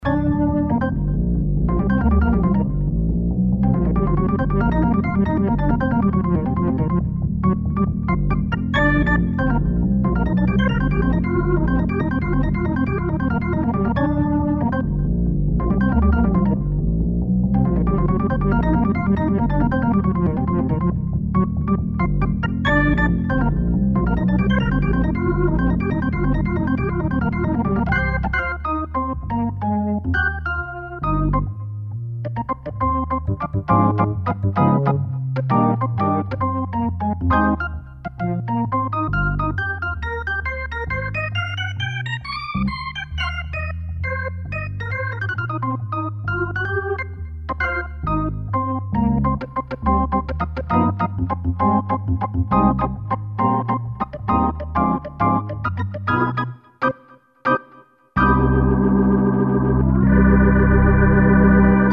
Tone Wheel Organ
editWAVETABLE many PCM are directly sampled from the authentic Hammond B3 with different Leslie and valves saturation or amplifications combinations.
demoAUDIO DEMO